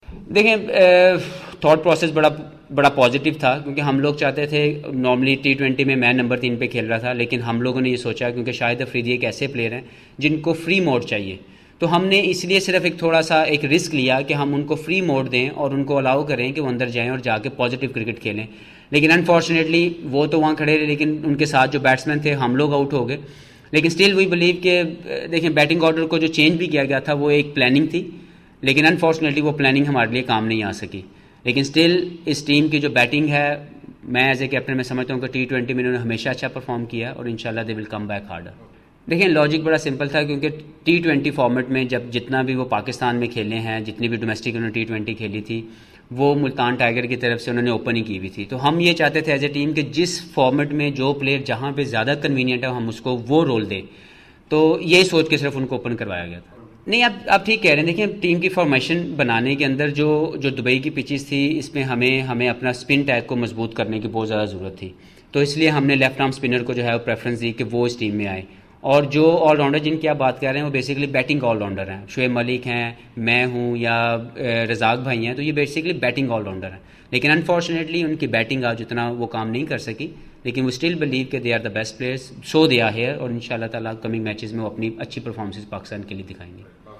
Mohammad Hafeez media conference (Urdu), II of II, 13 November